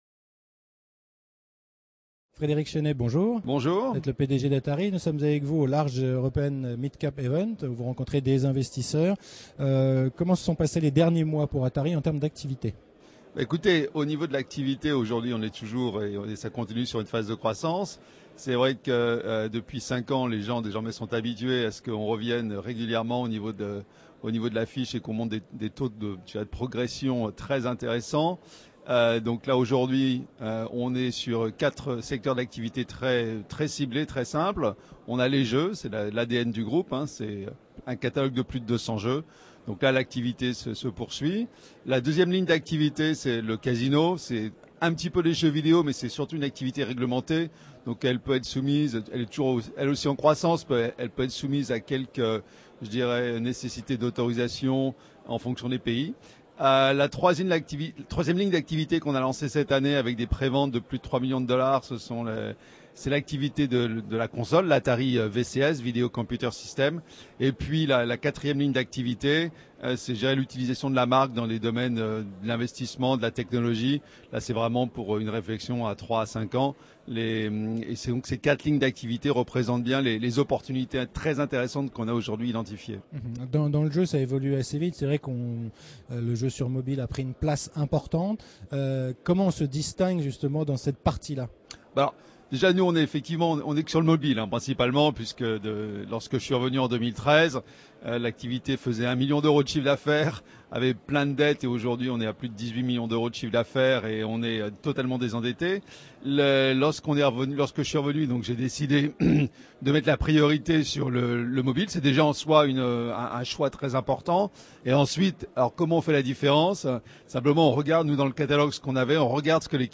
La Web Tv rencontre les dirigeants au Paris - European Large et Midcap Event